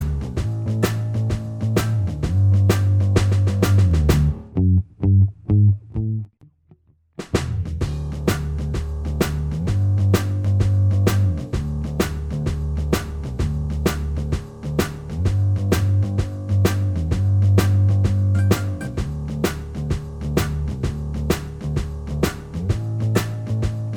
Minus Guitars Pop (1960s) 2:29 Buy £1.50